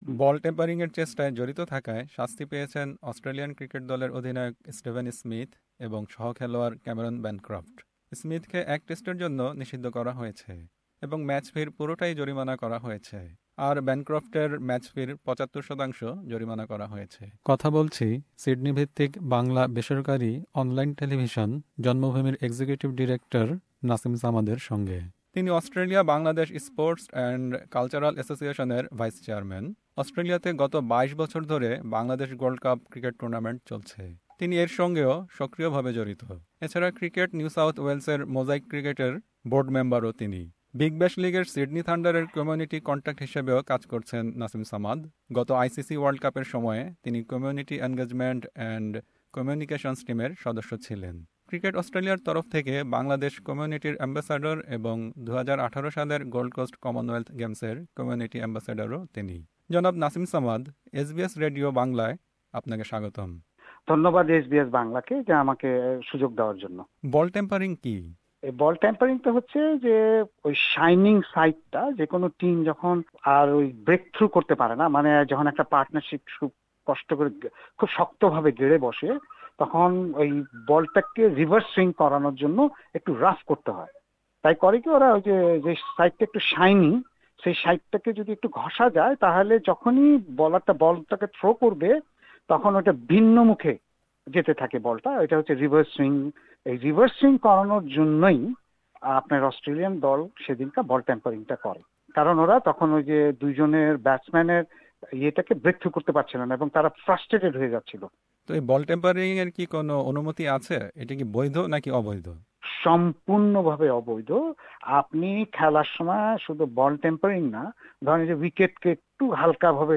full interview (in Bangla) with SBS Bangla in the audio player above.